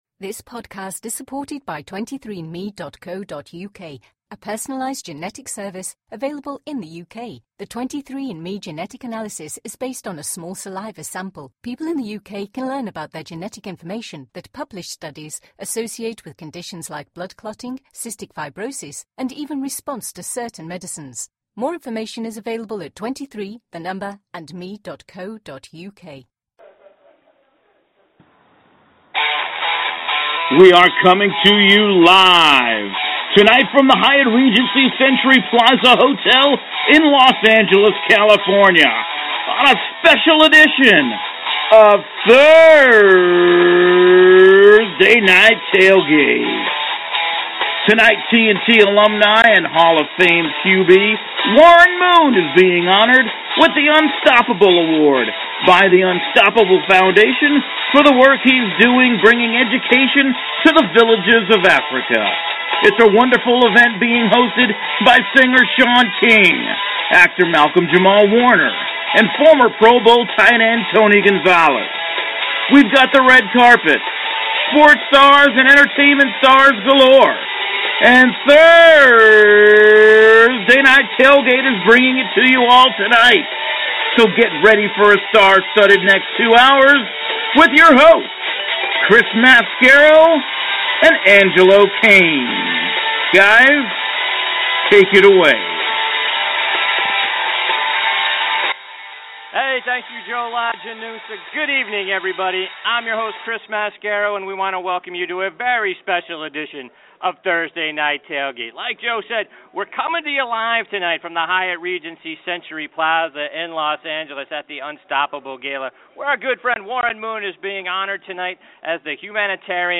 Special Edition of TNT live from the Unstoppable Gala Honoring Warren Moon
Tune in to a special edition Saturday edition of Thursday Night Tailgate broadcast live from the Hyatt Regency Century Plaza in Los Angeles at Unstoppable Gala honoring Hall of Fame QB Warren Moon. Warren is being recognized for the great work he's doing bringing education to the villages of Africa.